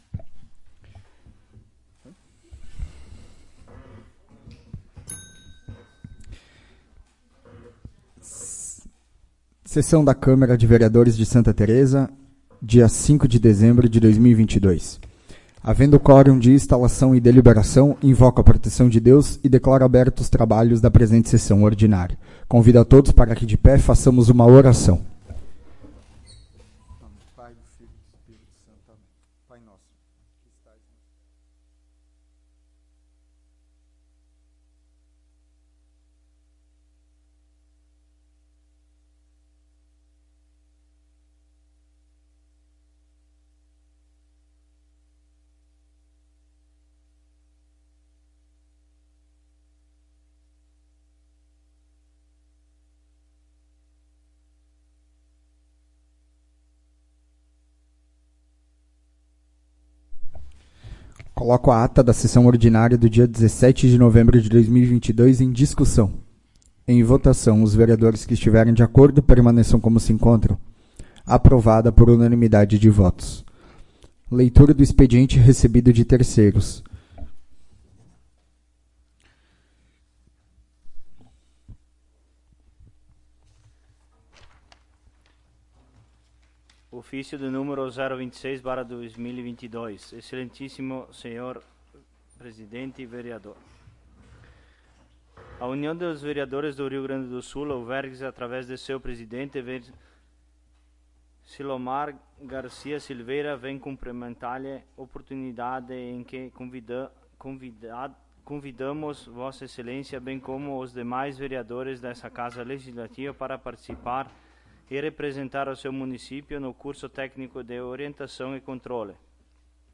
21° Sessão Ordinária de 2022
Áudio da Sessão